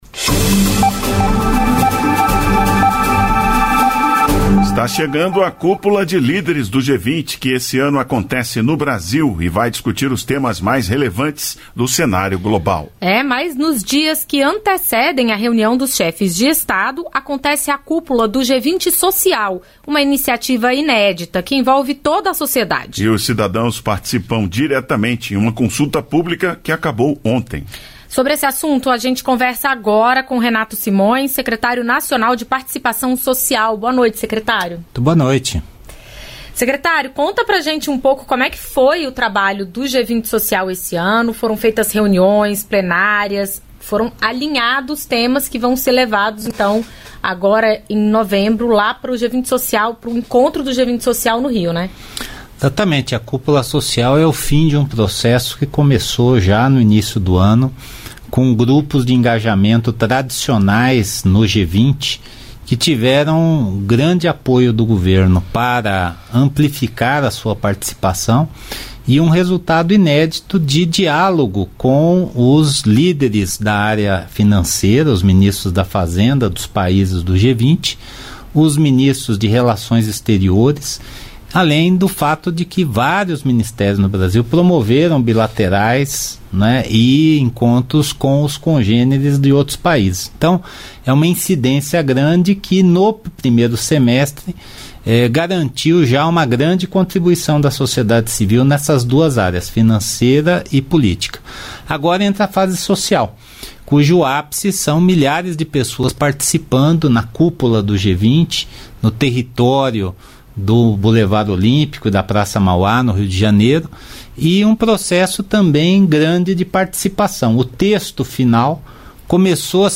Maurício Lyrio, Secretário de Assuntos Econômicos e Financeiros do Itamaraty
Entrevistas da Voz